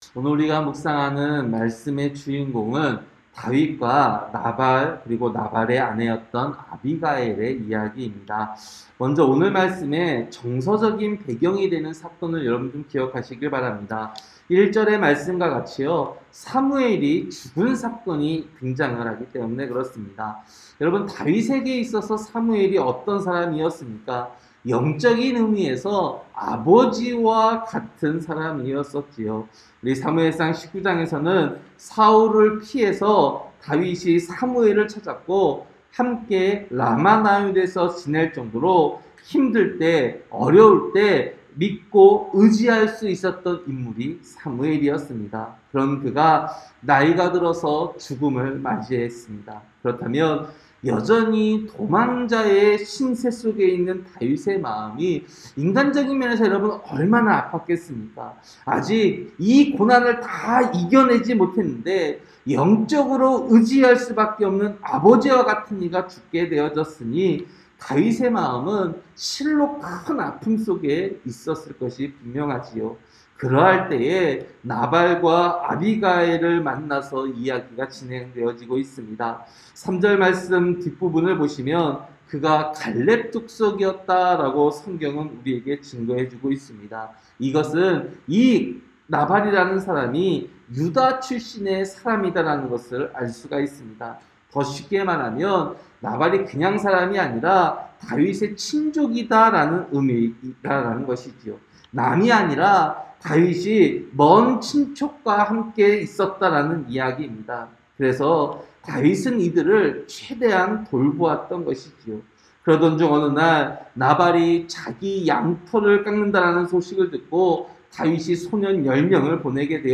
새벽설교-사무엘상 25장